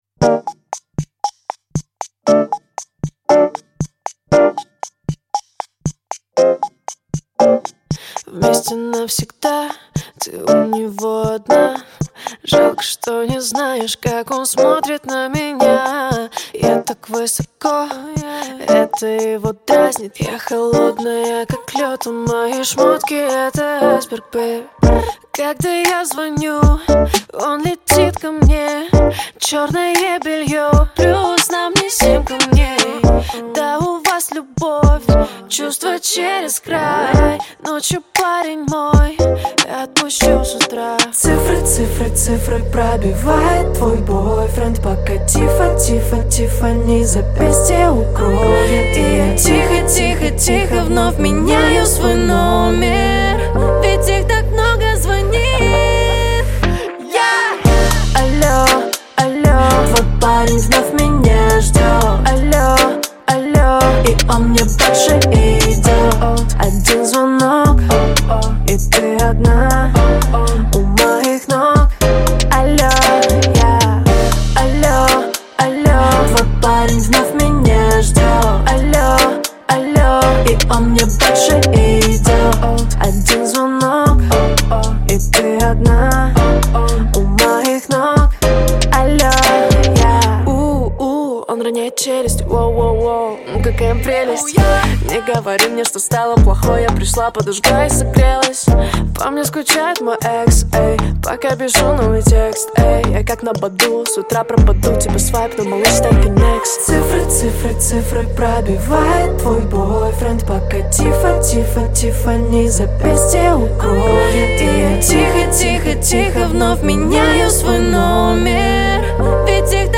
Поп-музыка
• Жанр песни: Жанры / Поп-музыка